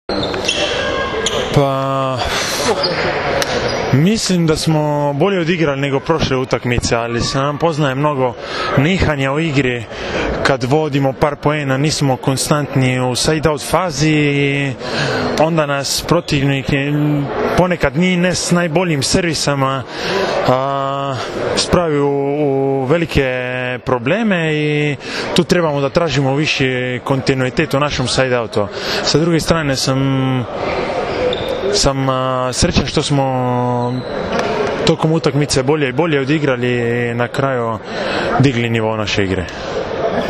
IZJAVA TINETA URNAUTA